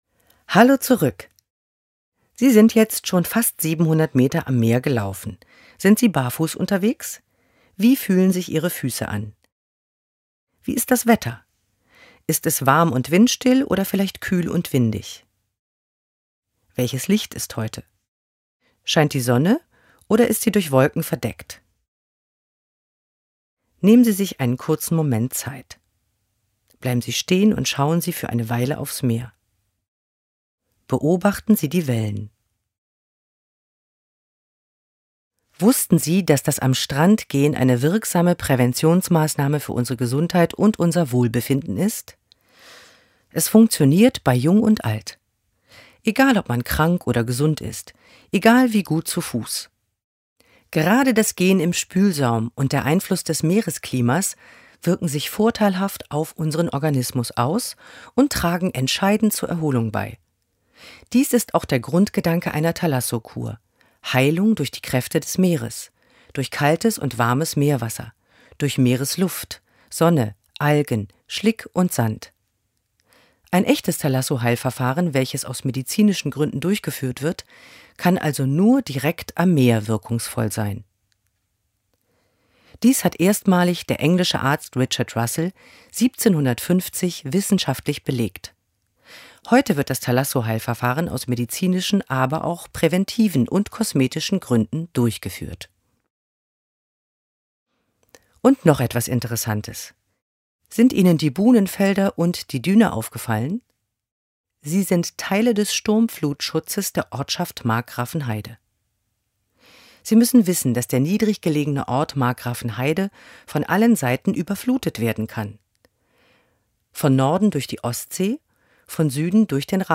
Audioguide
Verantwortlich für die Tonaufnahmen: Tonstudio an der Hochschule Stralsund.